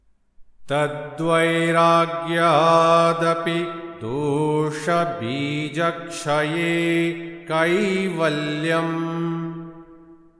Sutra Chanting